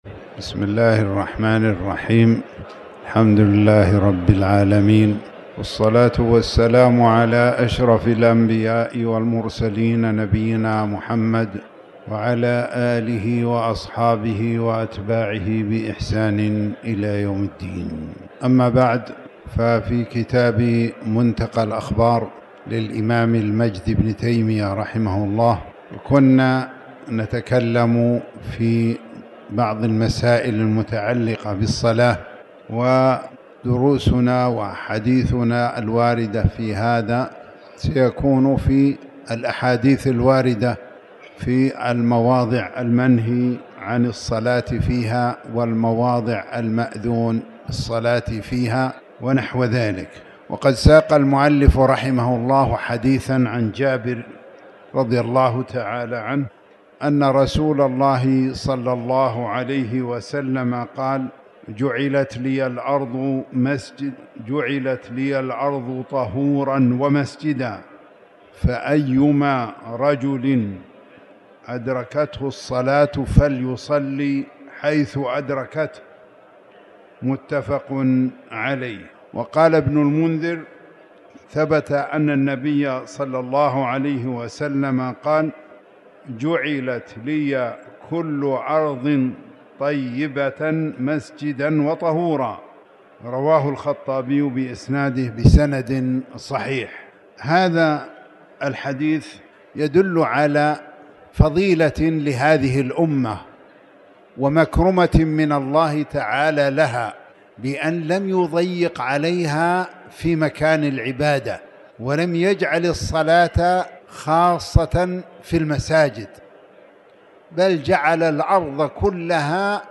تاريخ النشر ١٤ جمادى الآخرة ١٤٤٠ هـ المكان: المسجد الحرام الشيخ